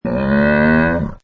Camel 10 Sound Effect Free Download